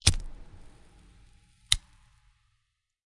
描述：关闭一次性打火机照明香烟;点击和关闭，在背景中燃烧微弱的烟草。
Tag: 打火机 一次性的 香烟